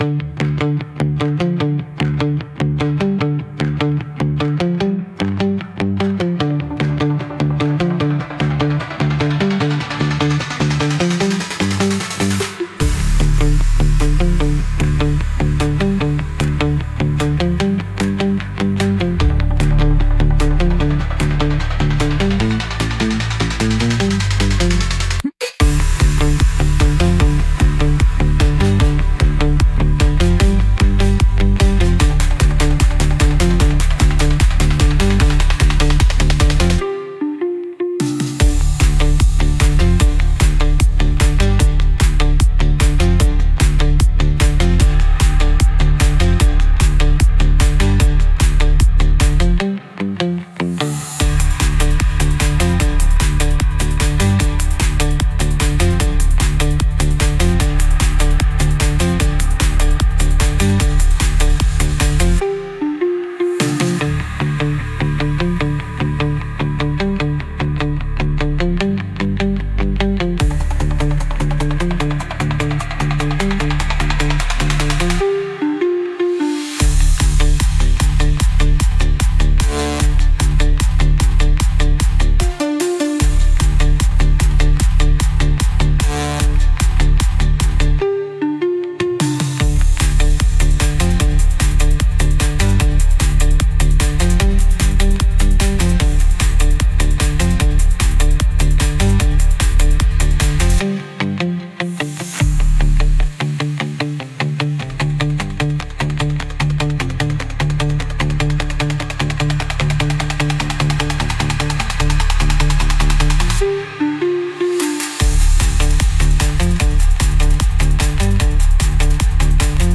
Home Download DANCE/ELECTRO/HOUSE